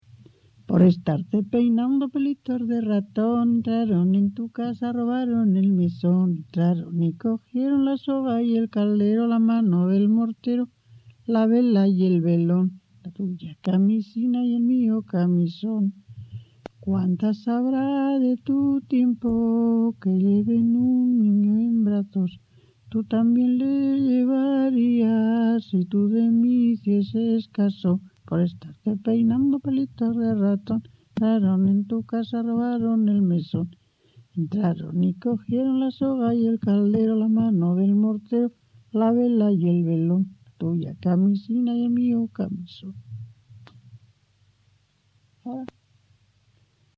Grabación realizada en La Overuela (Valladolid), en 1977.
Canciones populares Icono con lupa